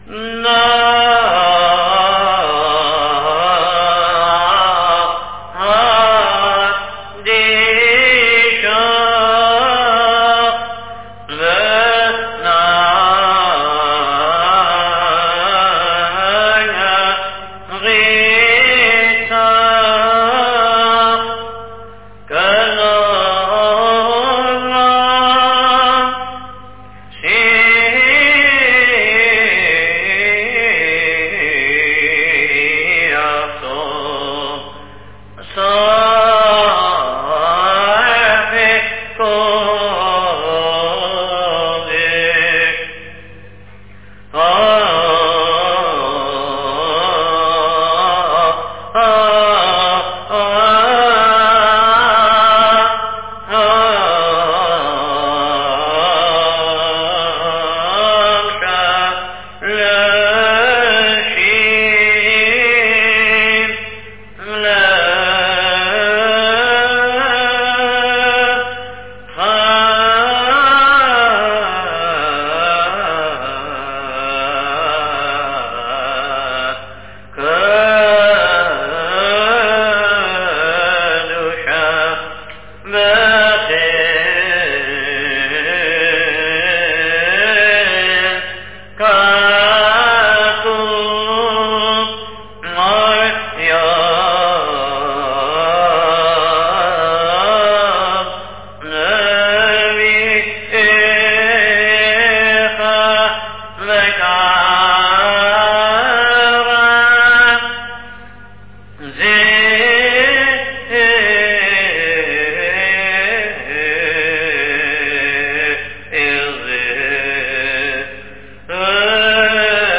Repetition Amida
קדושה ליום א,
NN26 Rosh Hashana-mincha kedusha dag 2-.mp3